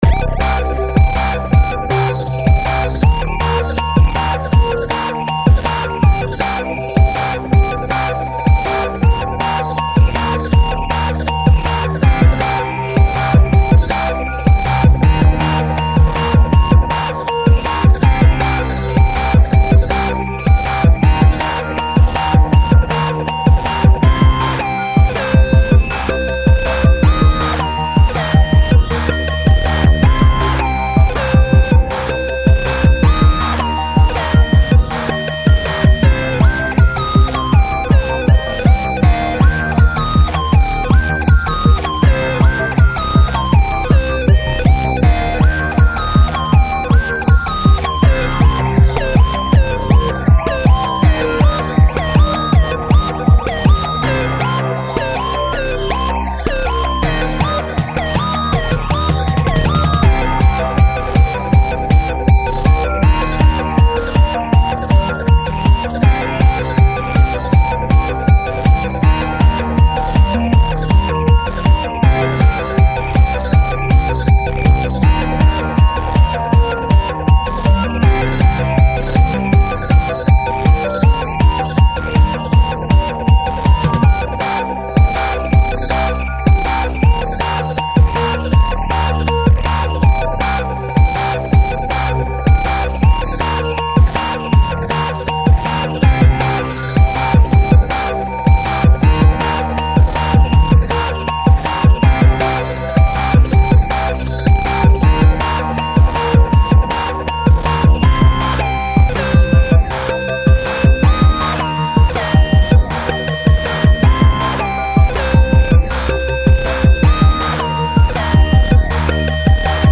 BPM160
Audio QualityPerfect (High Quality)
techno song
AUDIO: Cut from the website MP3.